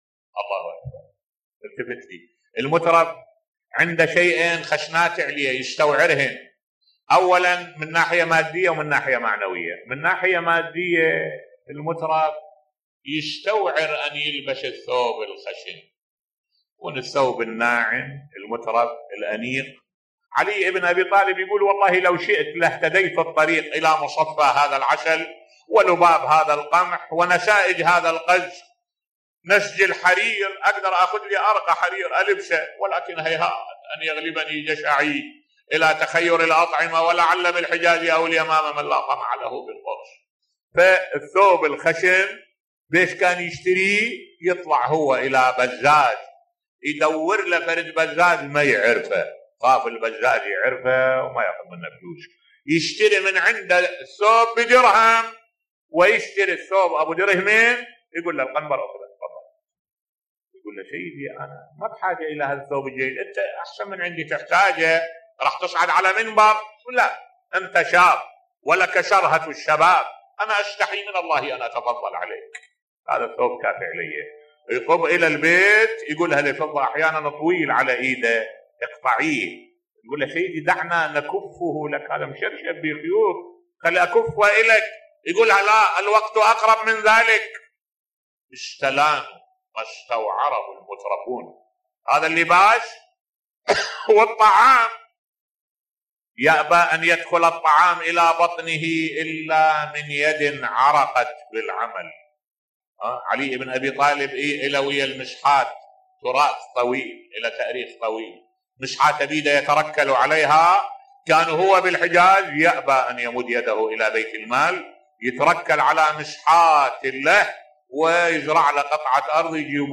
ملف صوتی زهد الإمام علي عليه السلام بصوت الشيخ الدكتور أحمد الوائلي